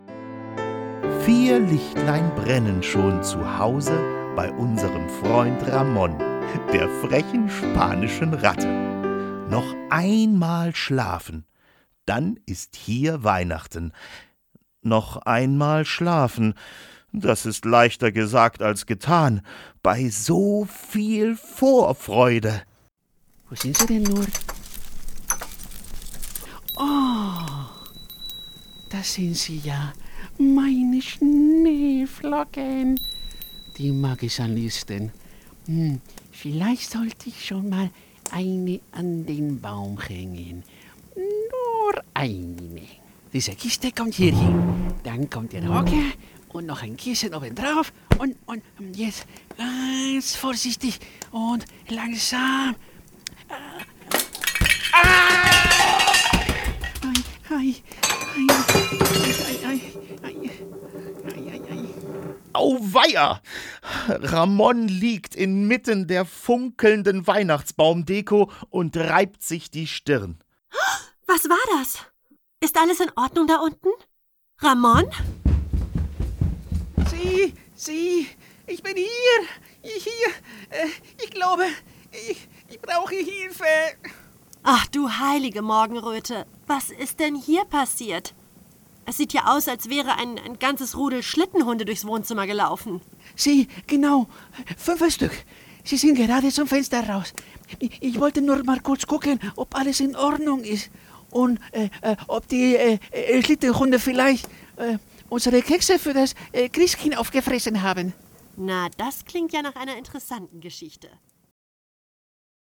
Mit der Gute-Nacht-Geschichte „Warten auf das Christkind“ und der frechen Ratte Ramon klingt der Tag fröhlich aus. Das Hörspiel mit Musik steigert vor dem Schlafengehen die Vorfreude der Kleinen auf Heiligabend und lässt sie ruhig und friedlich einschlafen.